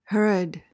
PRONUNCIATION: (HER-uhd) MEANING: noun: A cruel and wicked tyrant.